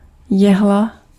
Ääntäminen
France: IPA: [pwɛ̃t]